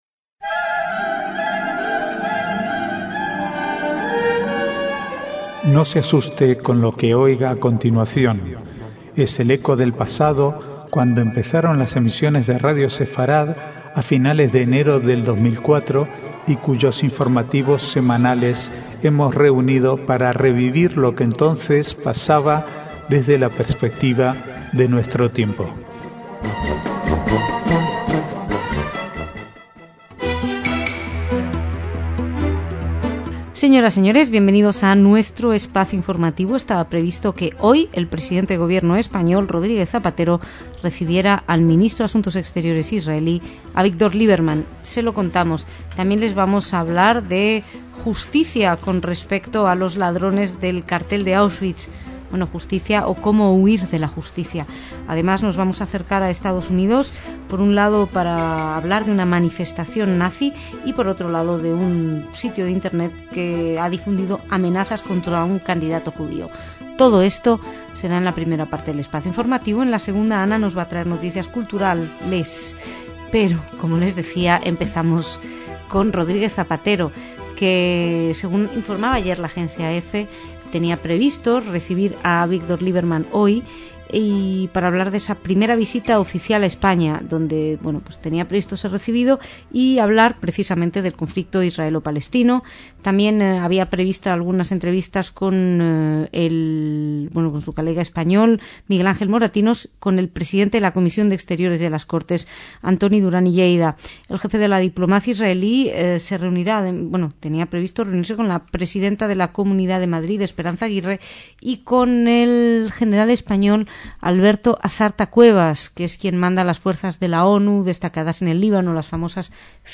Archivo de noticias del 22 al 28/4/2010